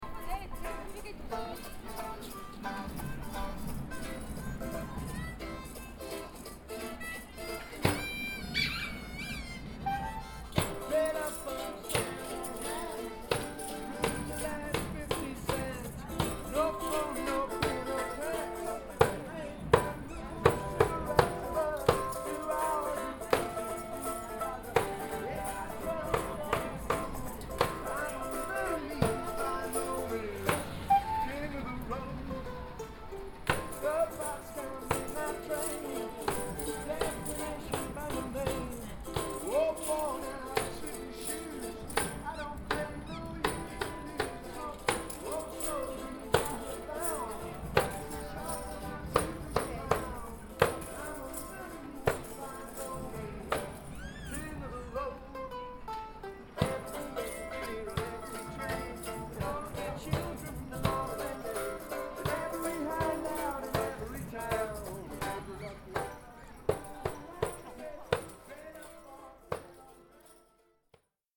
Rumore
La musica è un linguaggio universale ed i tamburi in lontananza sono quelli del carnevale storico di Ivrea Ivrea
Microfoni binaurali stereo SOUNDMAN OKM II-K / Registratore ZOOM H4n